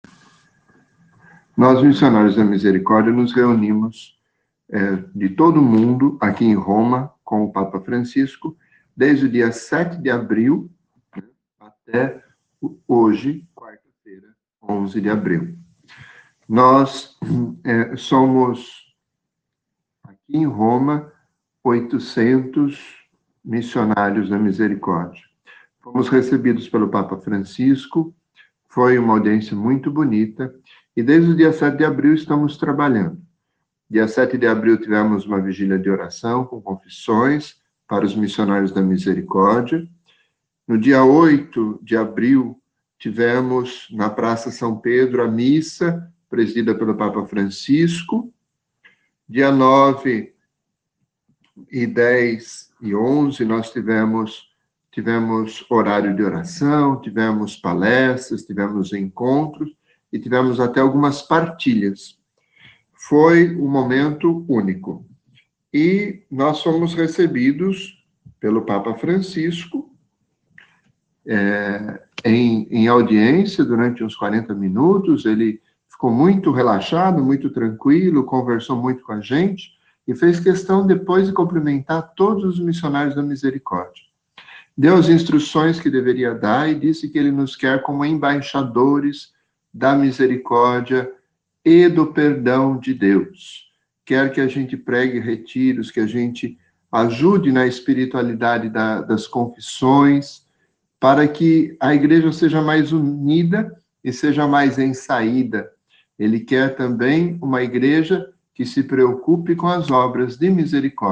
A Santa Missa com o Papa